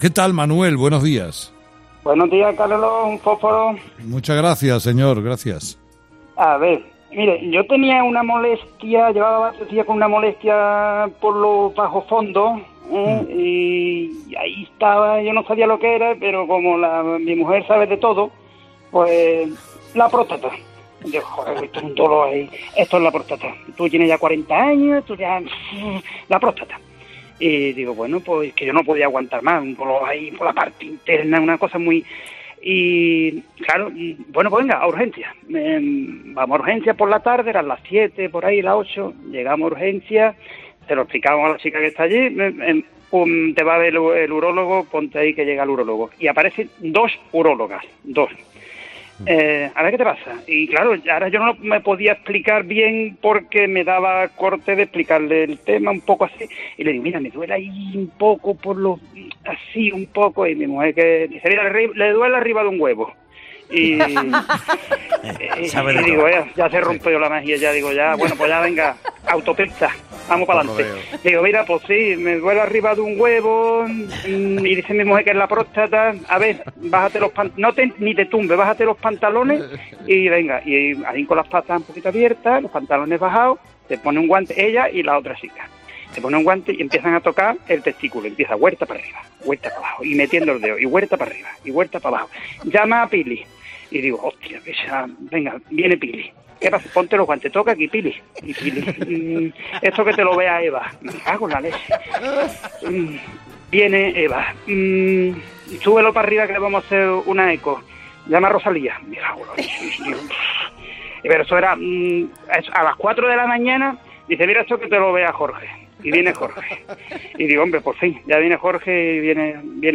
¿Le han hecho un tacto rectal? ¿Cómo ha sido la prueba? ¿Le ha dolido? Estas han sido algunas de las preguntas que han respondido los oyentes de Carlos Herrera.